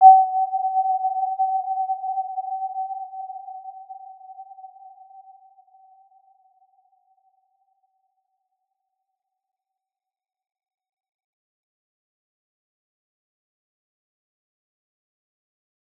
Warm-Bounce-G5-p.wav